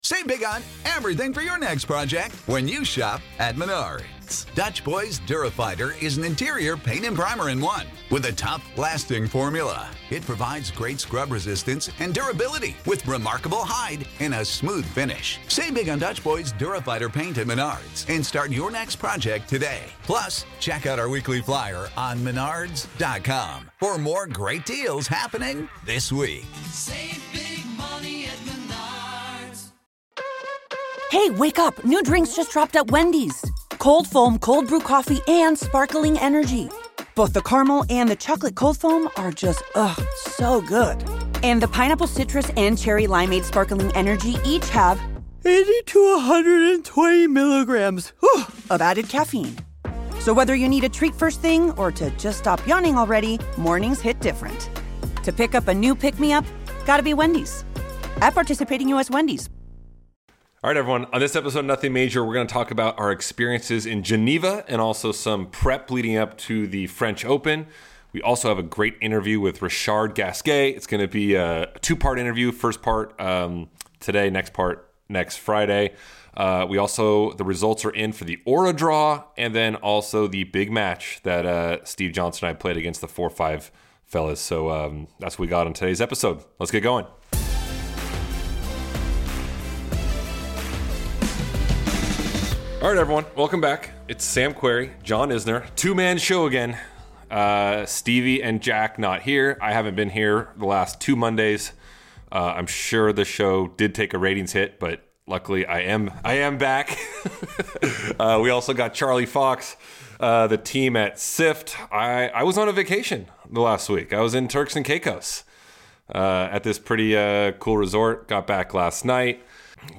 Former World No. 7 and 18-time non-major winner, Richard Gasquet joins this week’s episode for the first of a special two-part interview.